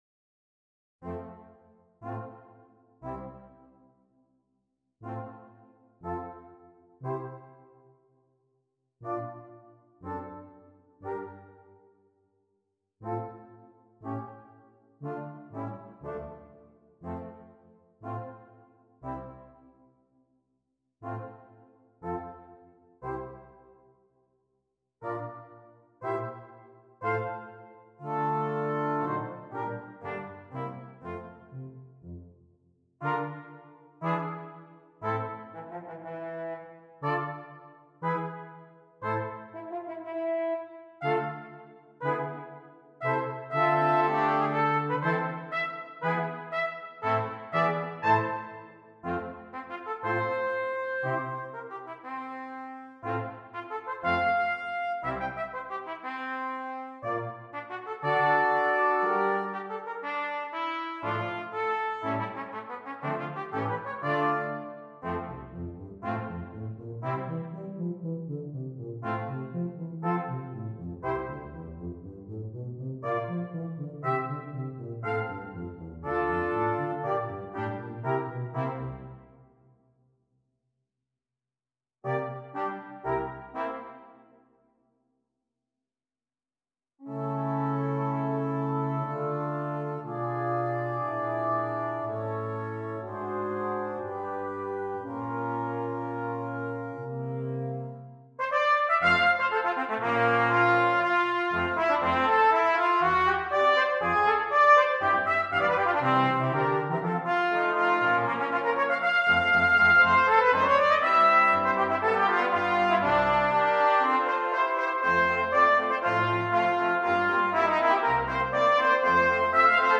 Brass Quintet and Solo Trumpet
for solo trumpet and brass quintet